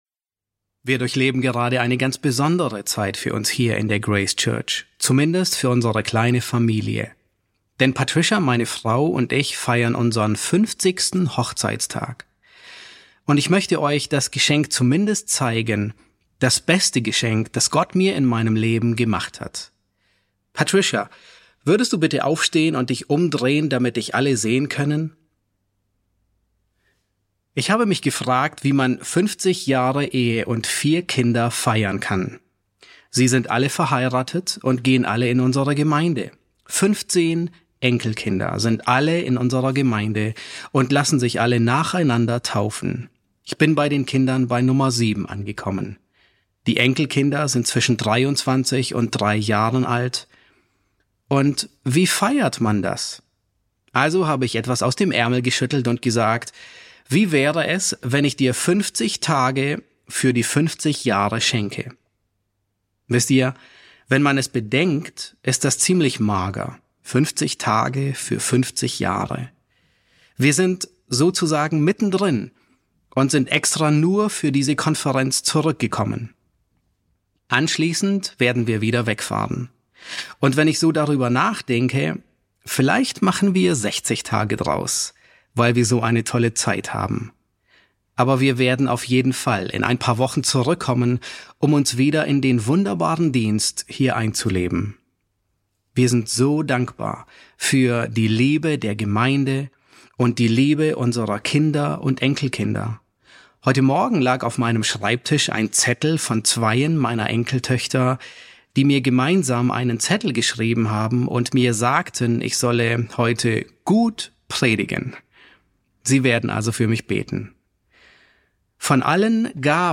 Predigten auf Deutsch Podcast